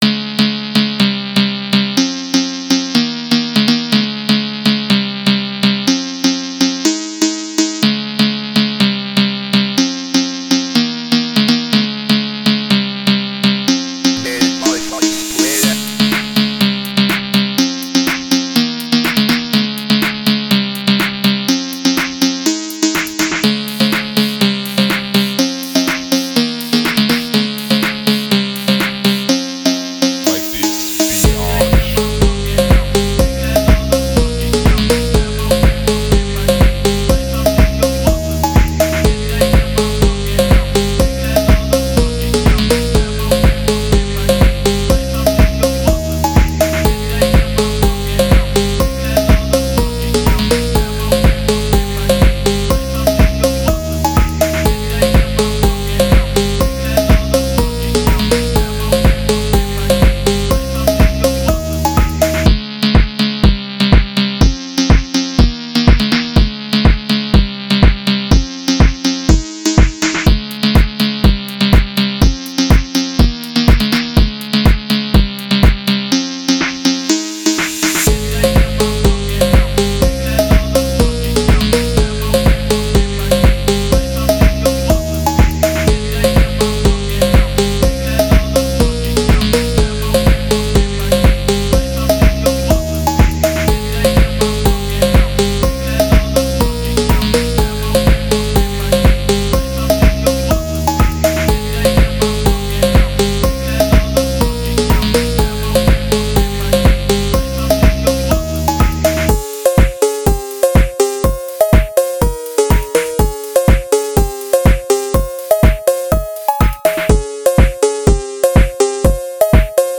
nvm i might start to use that watermark thing. i focused more on the melody for this song
phonk cowbell